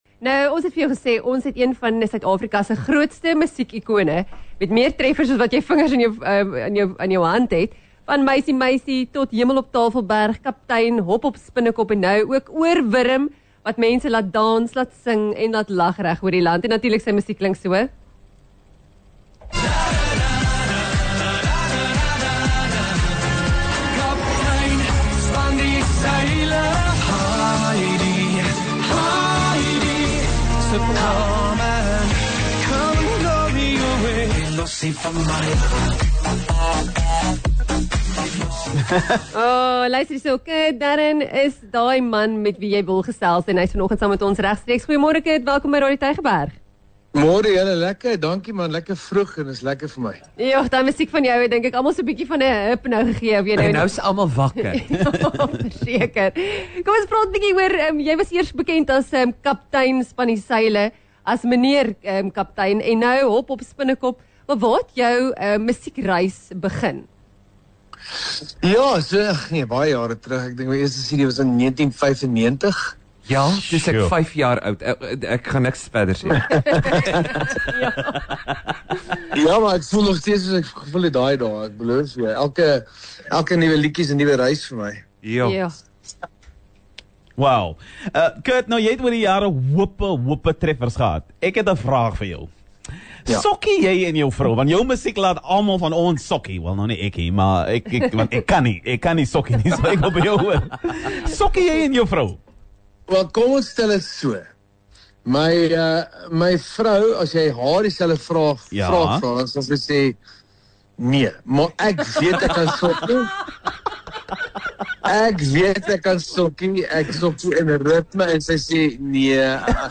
Kurt Darren het gesels met Die Real Brekfis (06:00-09:00) oor die lied waarop hy en sy vrou sokkie, hoe hy balans in die lewe vind en wat hom dryf om musiek te maak om mense te laat glimlag.